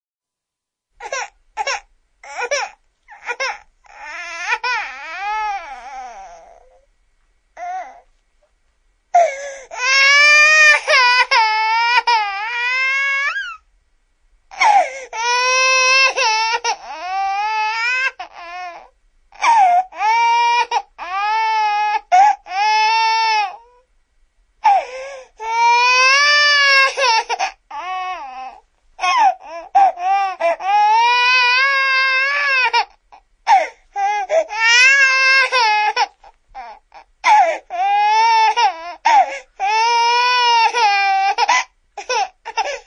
婴儿睡醒看不见妈妈哭声音效免费音频素材下载